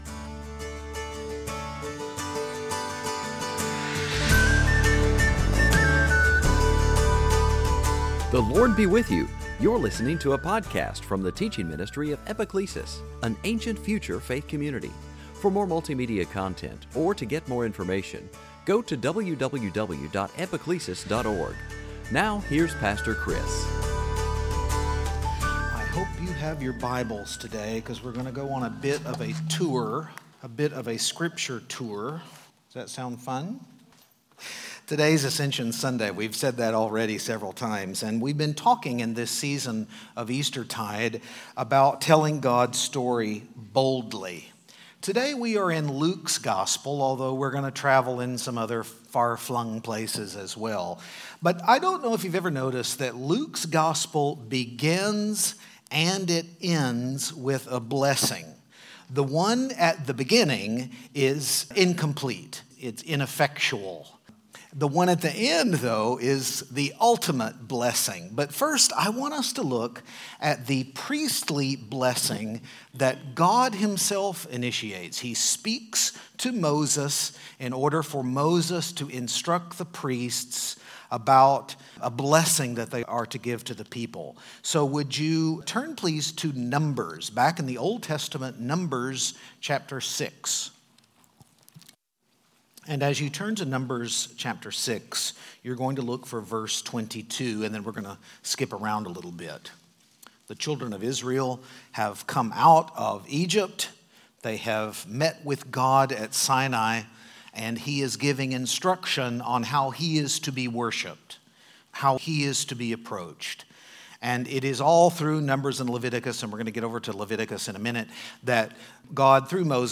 Series: Sunday Teaching
Service Type: Ascension Sunday